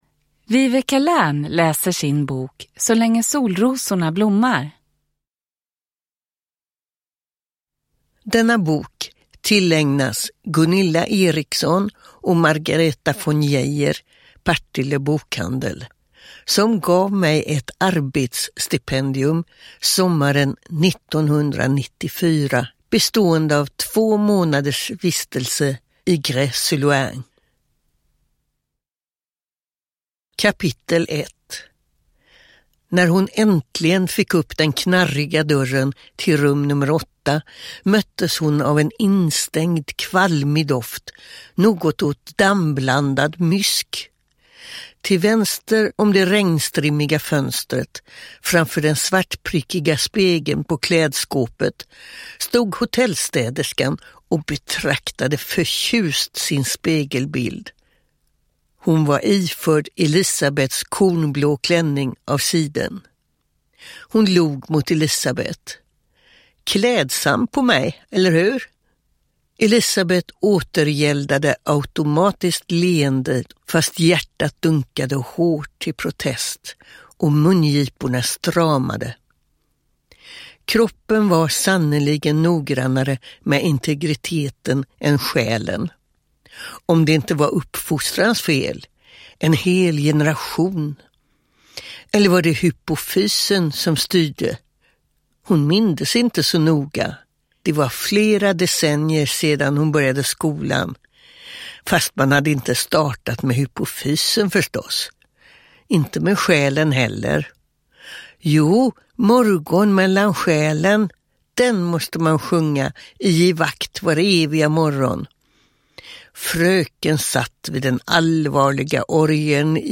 Uppläsare: Viveca Lärn
Ljudbok